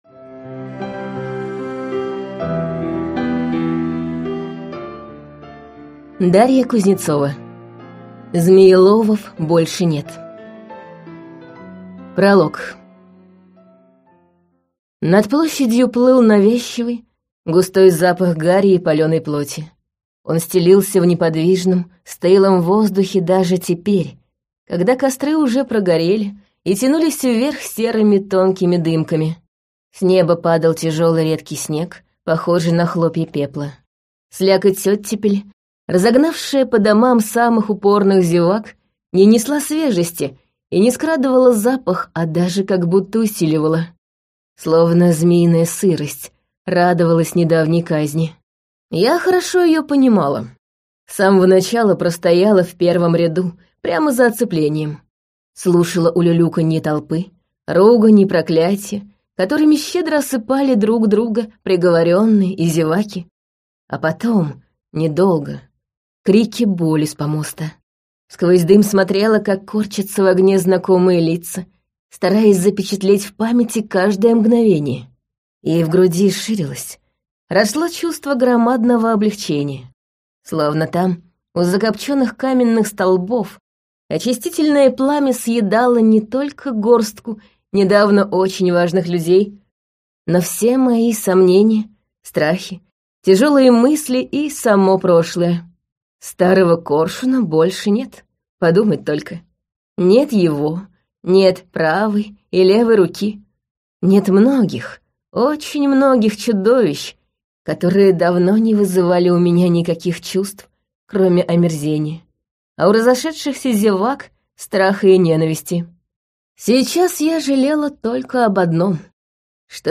Аудиокнига Змееловов больше нет | Библиотека аудиокниг
Прослушать и бесплатно скачать фрагмент аудиокниги